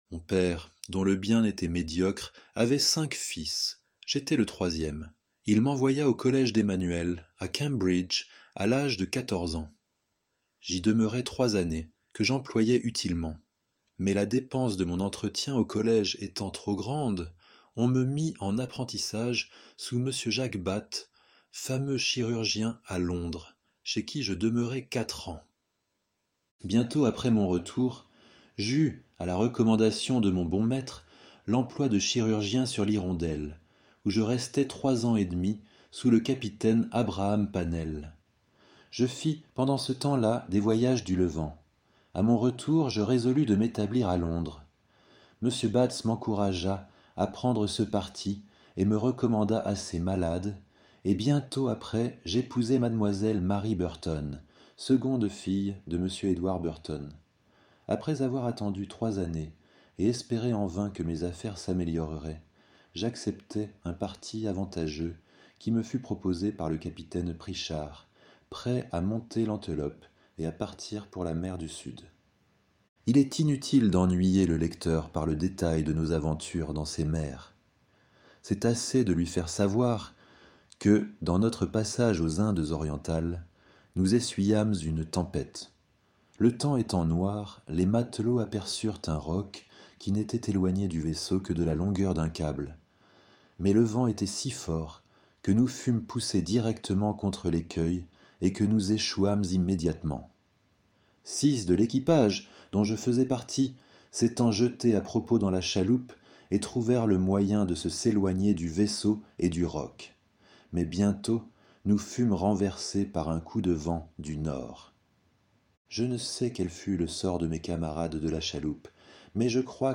Les-voyages-de-Gulliver-Audiobook-Contesdefees.com_.mp3